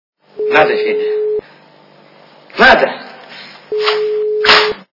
- Из фильмов и телепередач
При прослушивании Операция Ы и другие приключения Шурика - Надо Федя Надо качество понижено и присутствуют гудки.